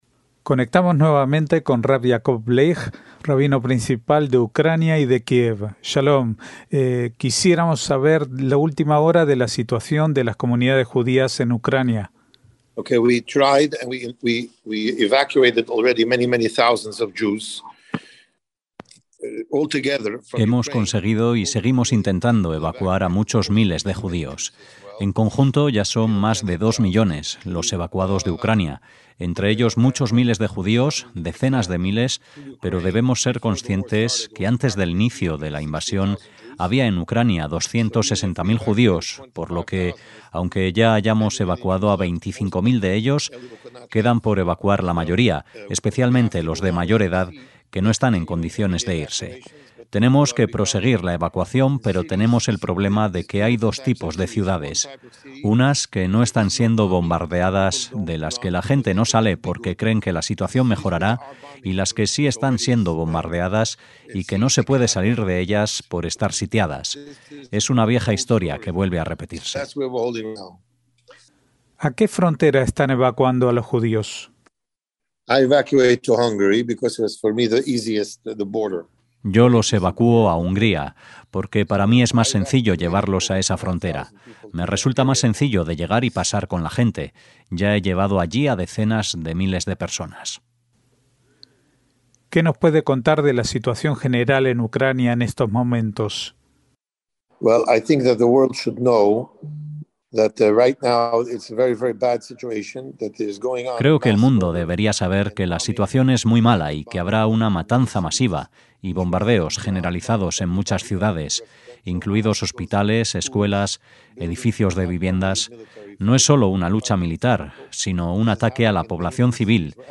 CASI EN DIRECTO - Volvimos a hablar con rab Yaakov Dov Bleich, rabino jefe de Kiev y Ucrania.